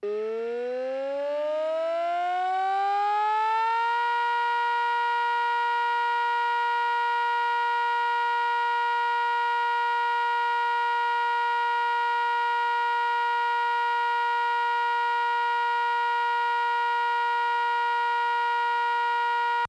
LISTEN: Gas Leak All Clear Signal - 21/06/2017
A siren to warn residents of an emergency at the gas terminal on the Isle of Grain will be tested today. This is what the 'all clear' signal will sound like.